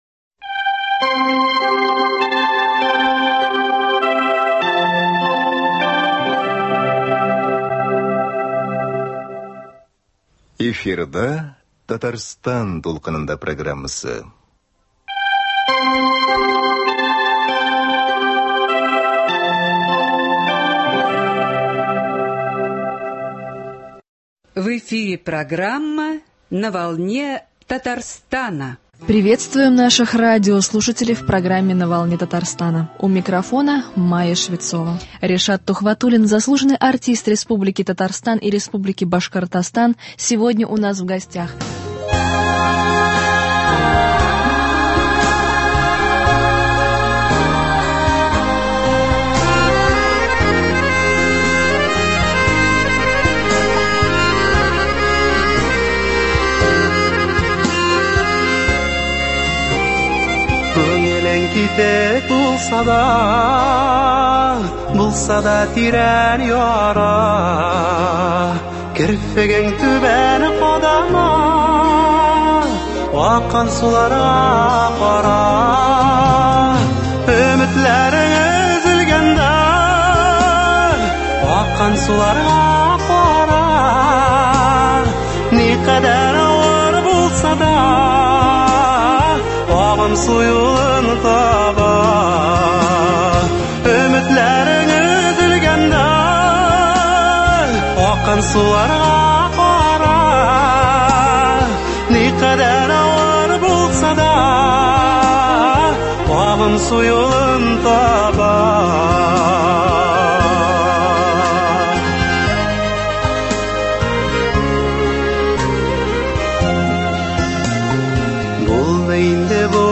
Беседа о тенденциях в современной татарской музыкальной культуре, образовании и о возможностях продвижения молодых исполнителей.